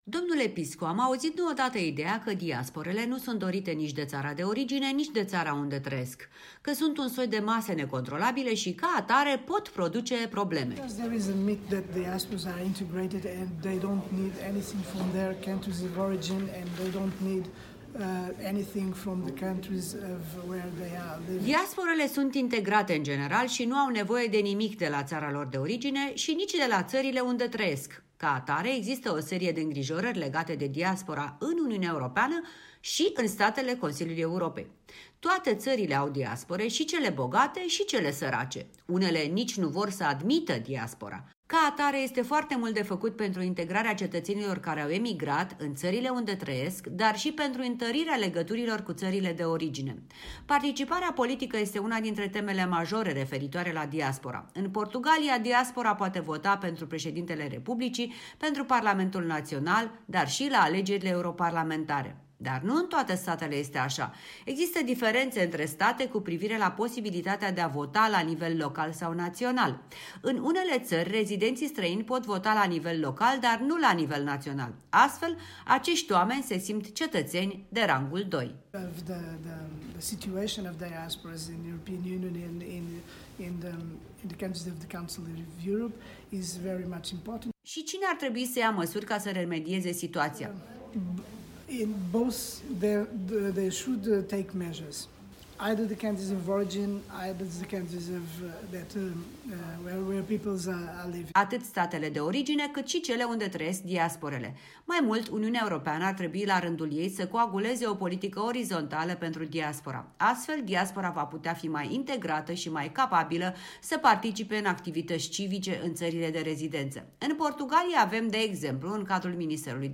Interviu cu Paolo Pisco, raportor APCE privind drepturile cetățenilor din diaspora